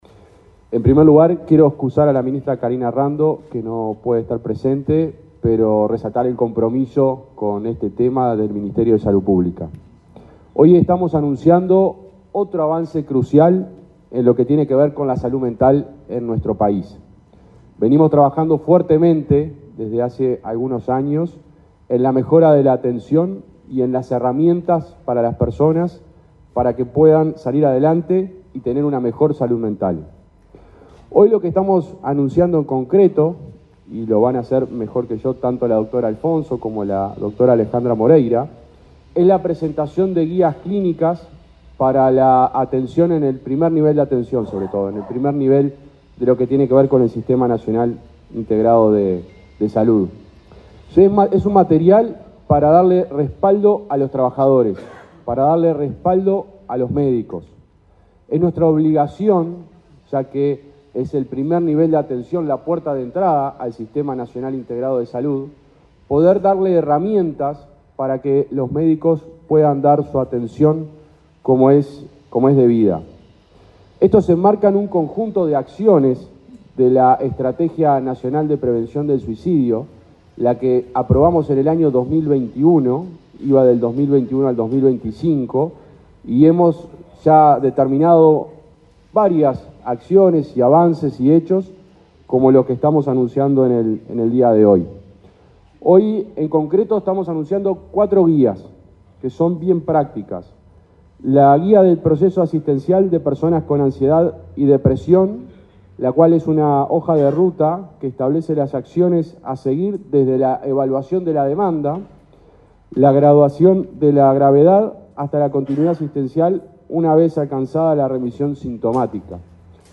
Palabras del subsecretario de Salud Pública, José Luis Satdjian
Palabras del subsecretario de Salud Pública, José Luis Satdjian 04/12/2024 Compartir Facebook X Copiar enlace WhatsApp LinkedIn Este miércoles 4 en Montevideo, el subsecretario de Salud Pública, José Luis Satdjian, participó en el lanzamiento de las guías de práctica clínica en salud mental.